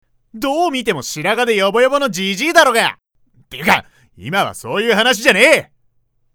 ・３つ年が上なので、やや大人びているところも
【サンプルボイス】